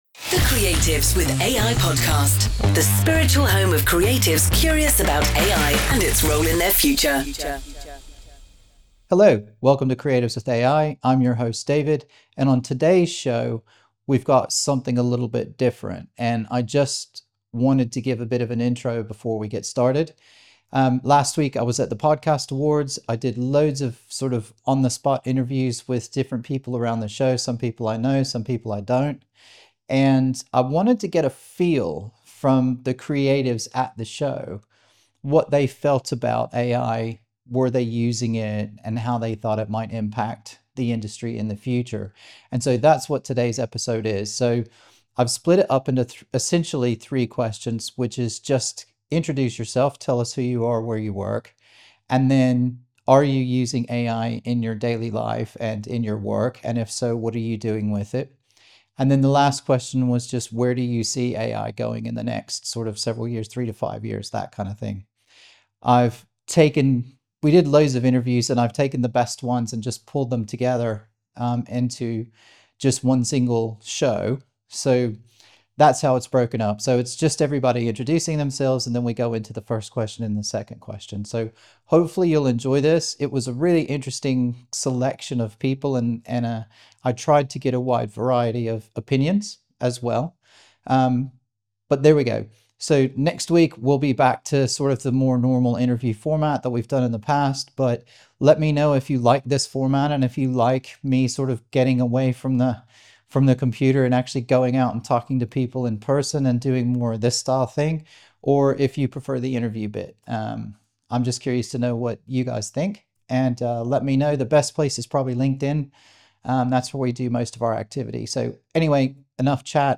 Podcast Show Vox Pop Interviews
e54-podcast-show-vox-pop-interviews-by-question.mp3